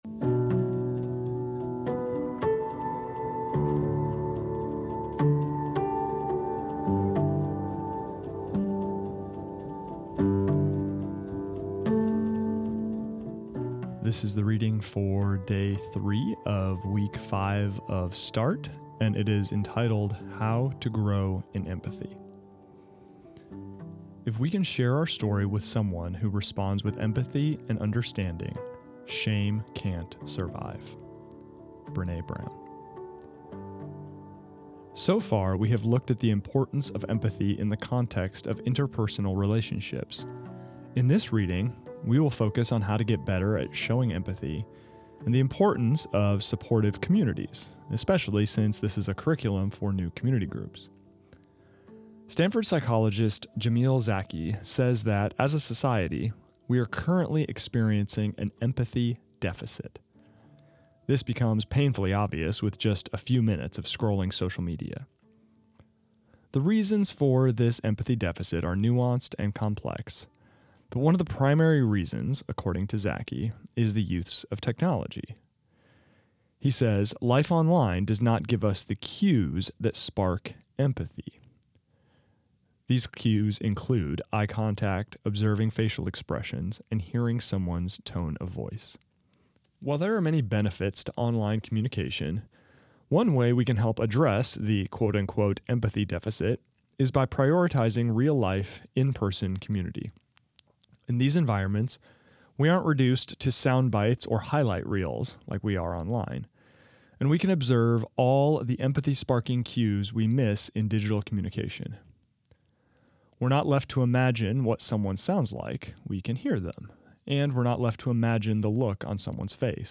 This is the audio recording of the third reading of week seven of Start, entitled How to Grow in Empathy.